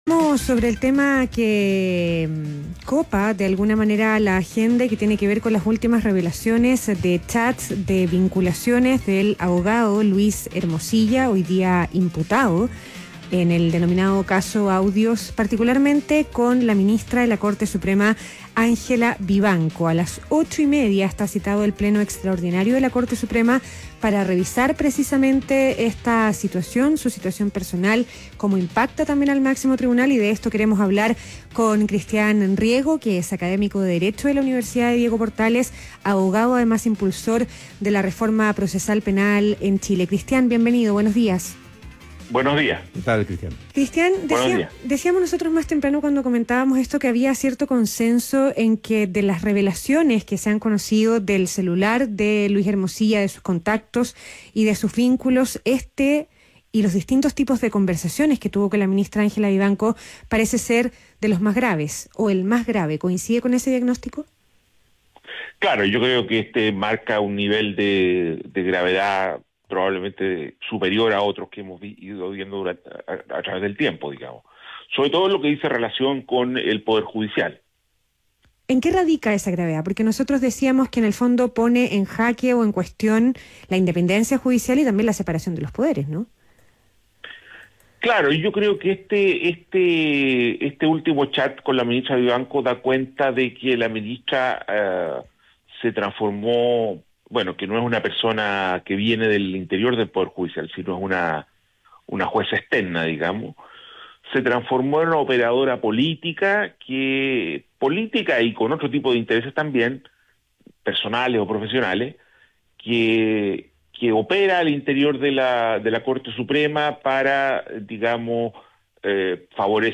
ADN Hoy - Entrevista